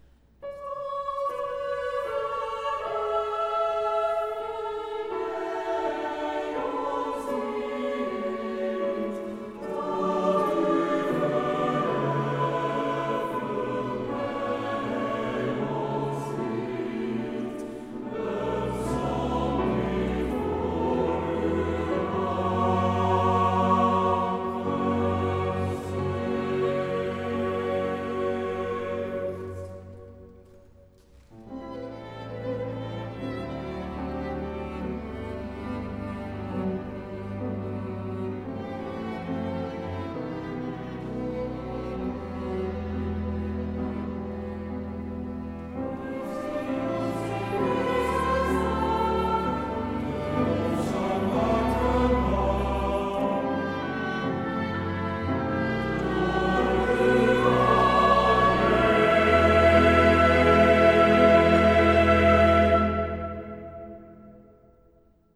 CANTATE